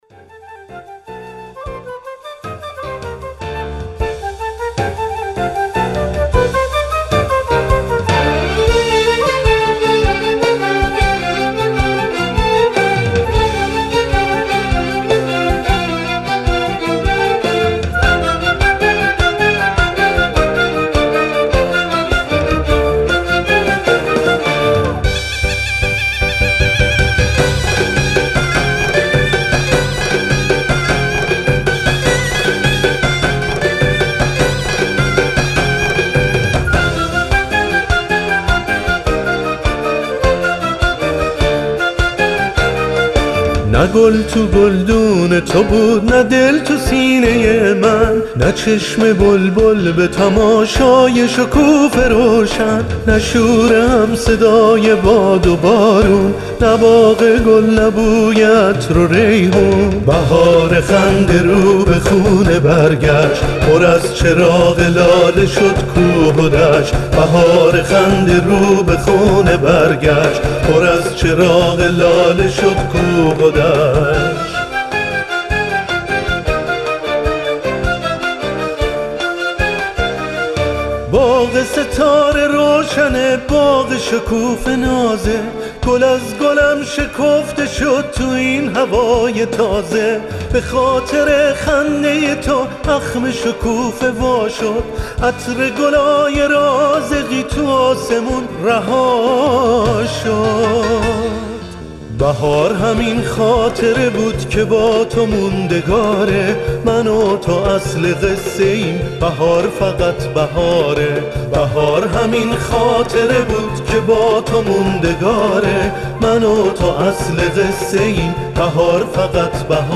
ترانه بهاری